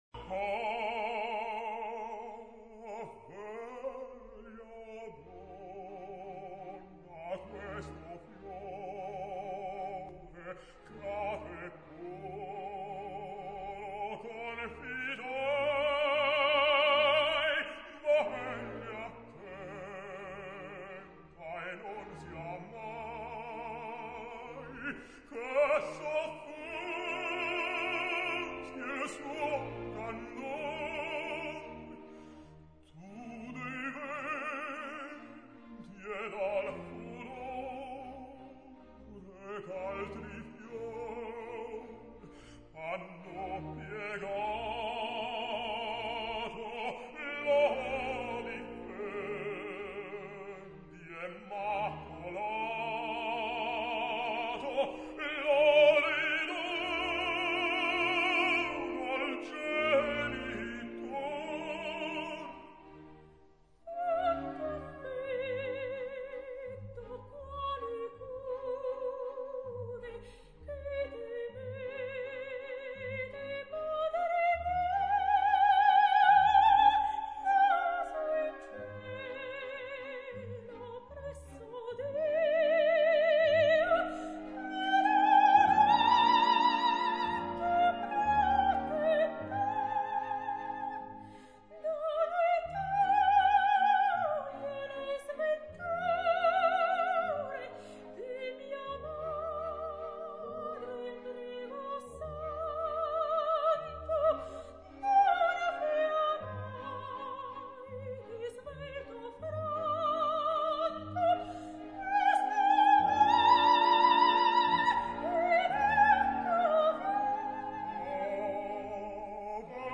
per soprano, baritono e banda
VOCI E BANDA